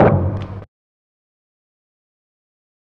kits/OZ/Percs/Godzilla Perc 3.wav at 32ed3054e8f0d31248a29e788f53465e3ccbe498
Godzilla Perc 3.wav